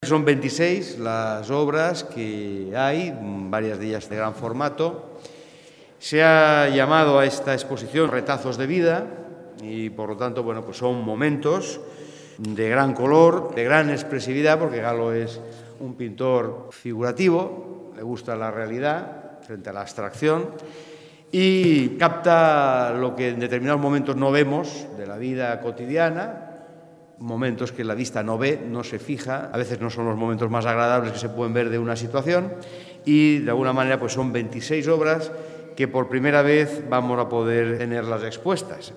El diputado de Cultura y Educación, César Augusto Asencio, ha presentado esta mañana todos los detalles de esta exposición que, bajo el título Retazos de vida’,ofrece una visión artística capaz de emocionarnos ya que, a través de una paleta única que destaca especialmente por su gran colorido, nos aproxima a los conceptos de arte contemporáneo, vanguardia hiperrealista y realismo mágico”.
Inauguracion-Retazos-de-Vida-CORTE-Cesar-Augusto-Asencio.mp3